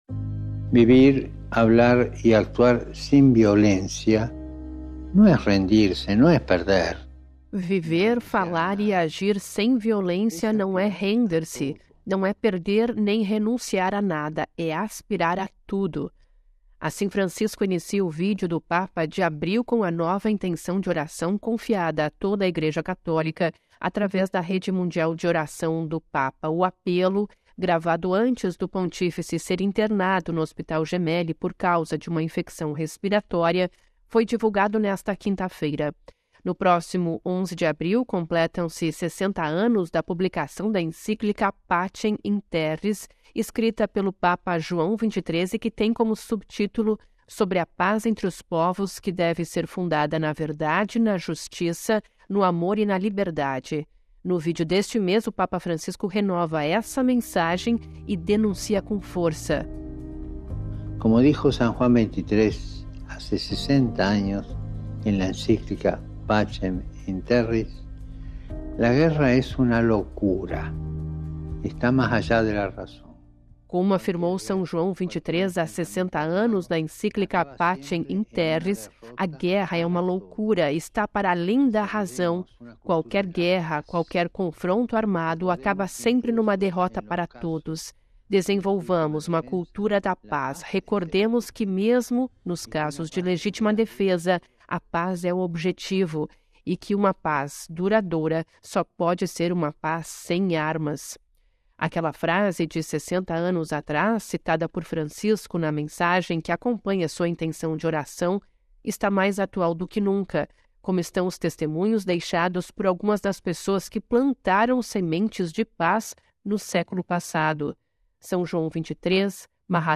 Ouça a reportagem com a voz do Papa e compartilhe